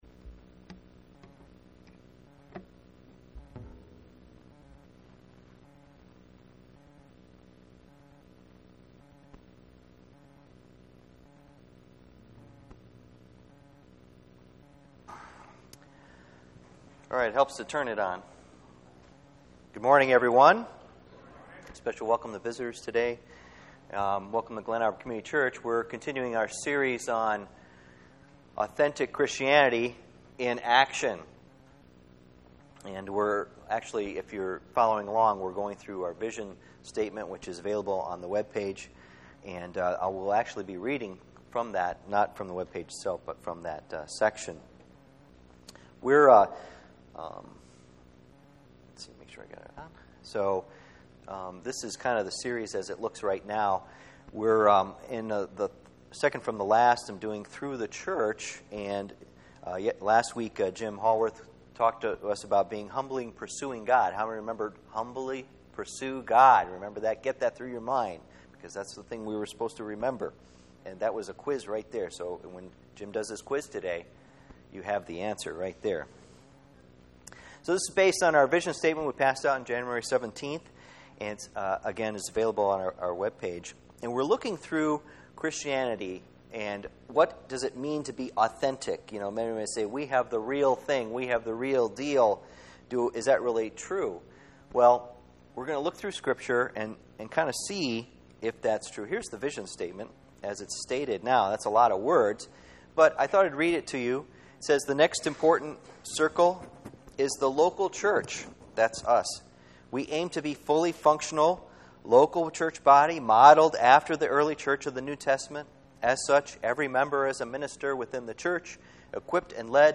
Church Vision Series Service Type: Sunday Morning %todo_render% « Authentic Christianity…